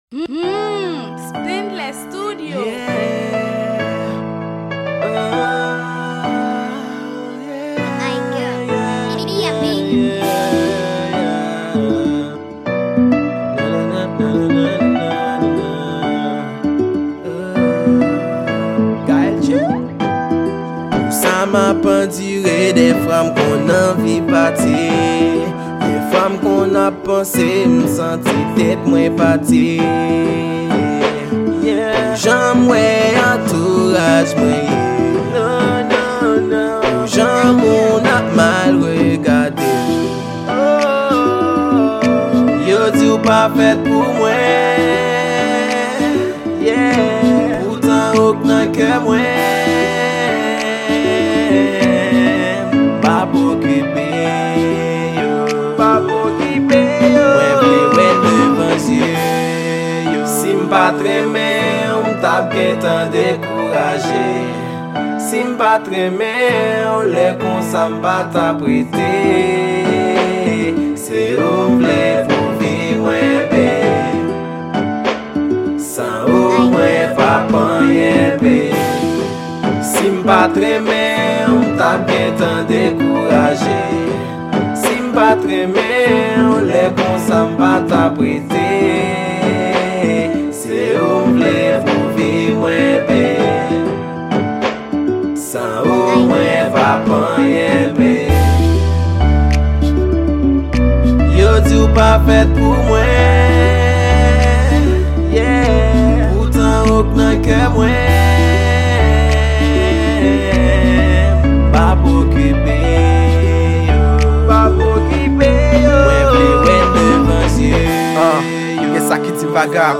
Genre : RNB